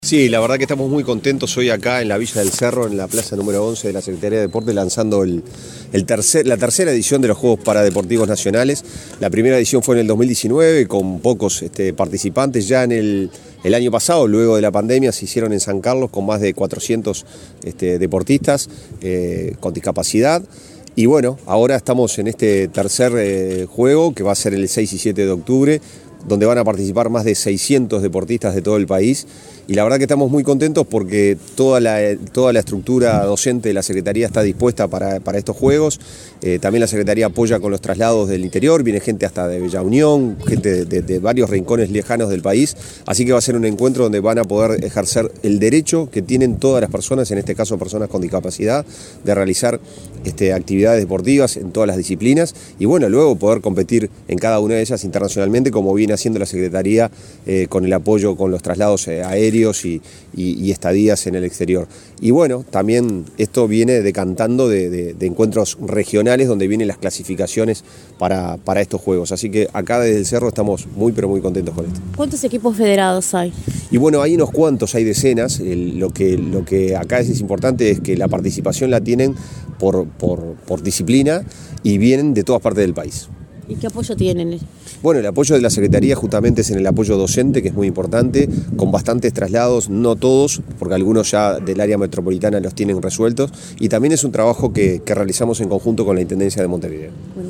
Declaraciones del subsecretario nacional del Deporte, Pablo Ferrari
Este martes 5, en la plaza de deportes n.° 11, el subsecretario nacional del Deporte, Pablo Ferrari, participó en el lanzamiento de los III Juegos